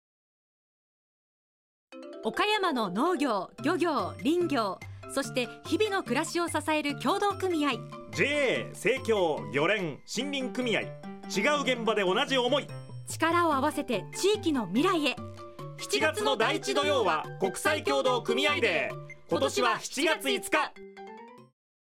ラジオCMはこちら